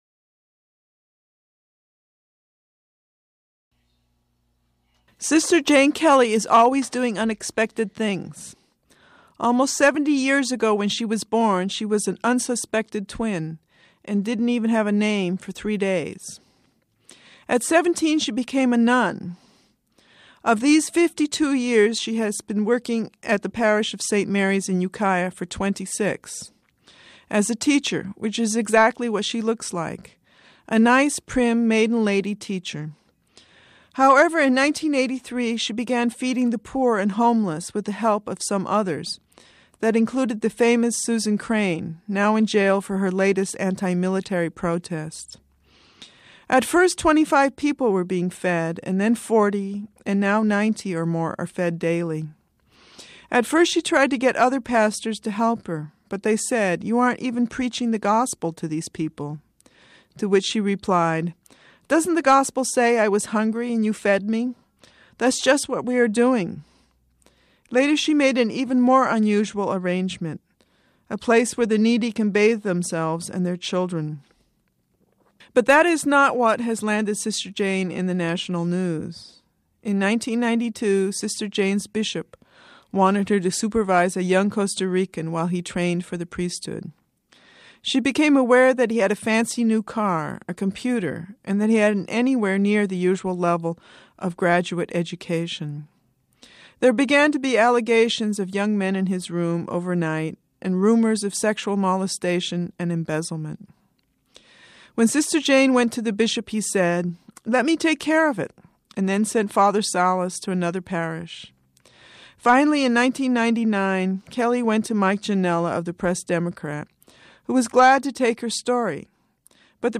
a piece read on KMUD in 1999